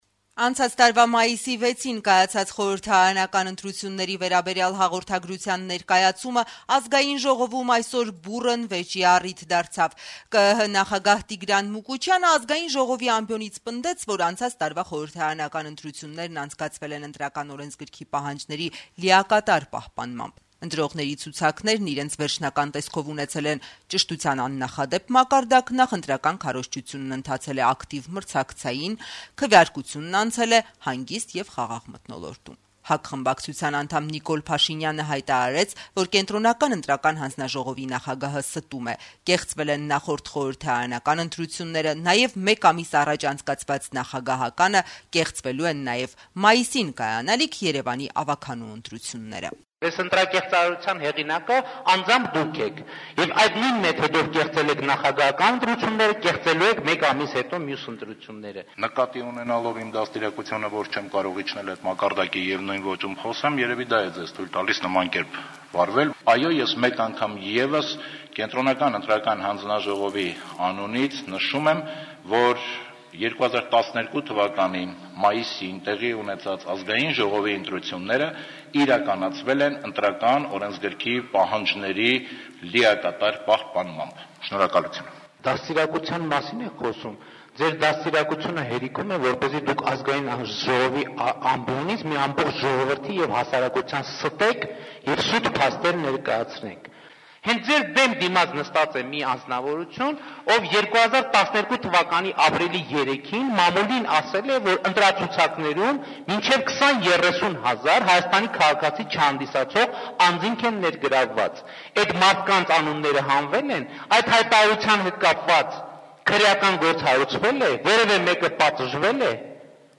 Բուռն բանավեճ ԱԺ-ում` Նիկոլ Փաշինյանի եւ Տիգրան Մուկուչյանի միջեւ